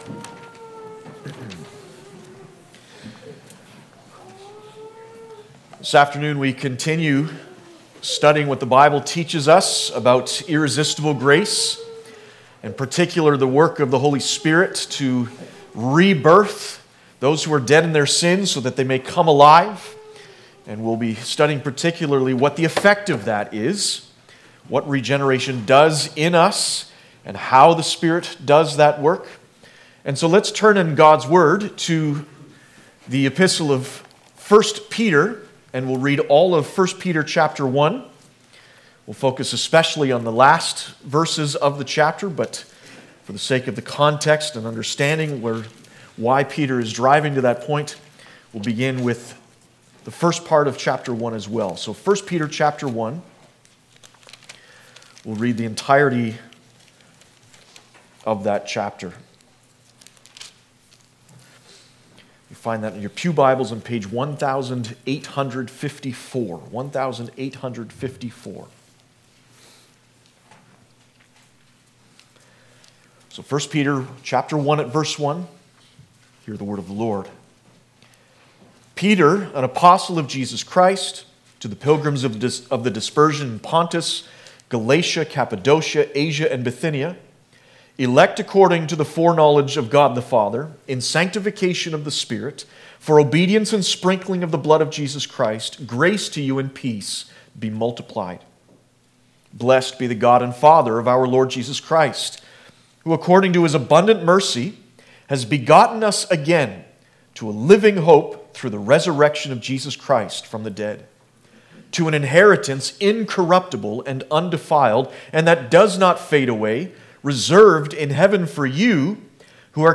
Passage: 1 Peter 1 Service Type: Sunday Afternoon